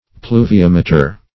Pluviameter \Plu`vi*am"e*ter\, n.